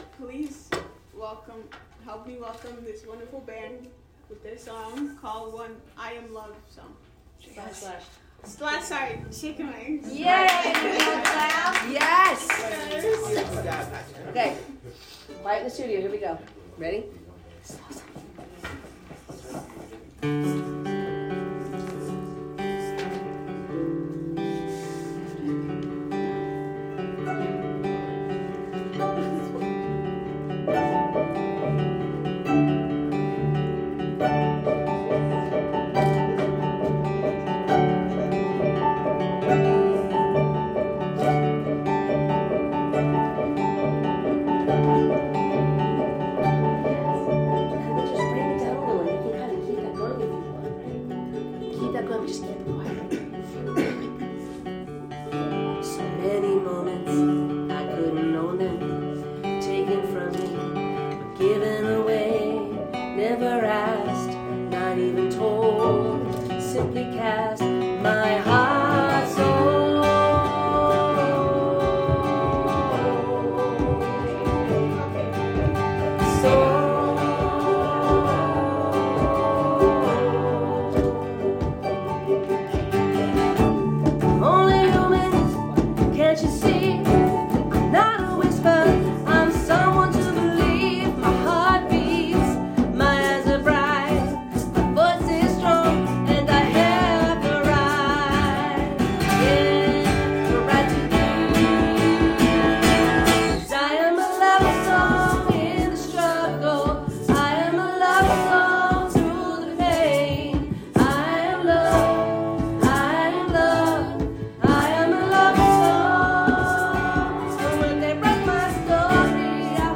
Chicken-Wingz-C3-2023-Community-Song.m4a